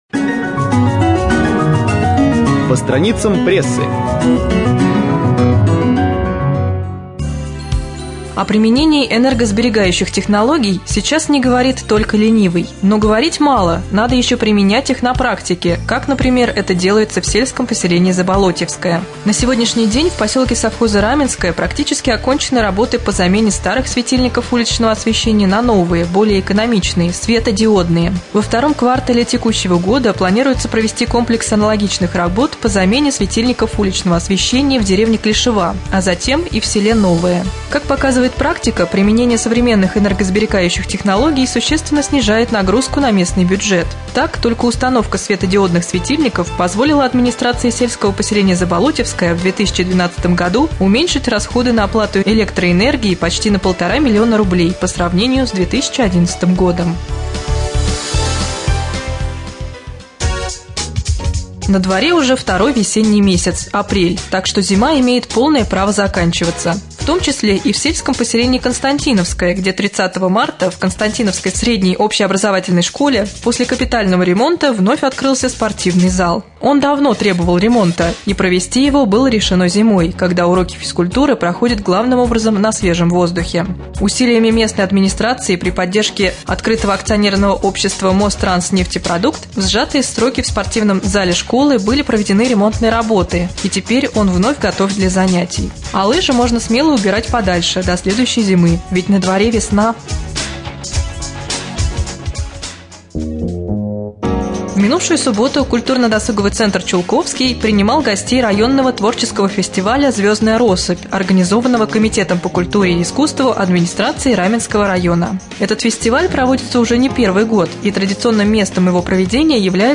10.04.2013 в эфире раменского радио - РамМедиа - Раменский муниципальный округ - Раменское